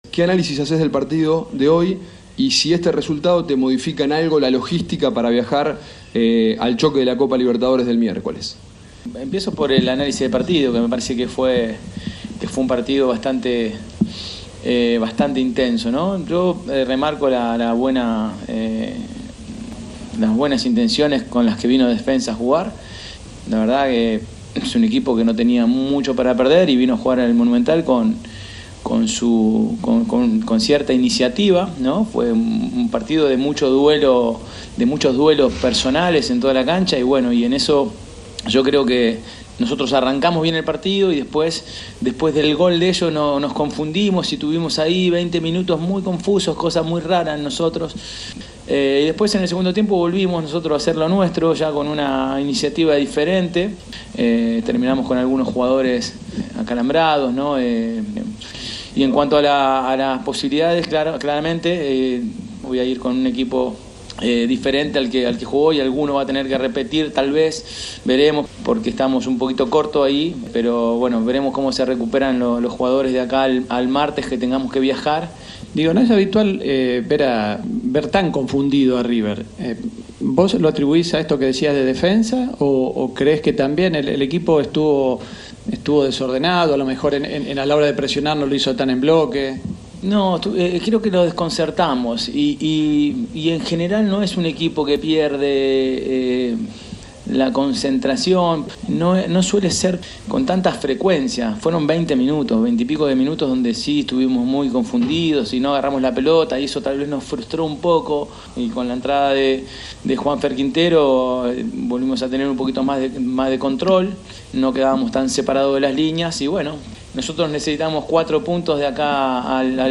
(Marcelo Gallardo, DT de River Plate)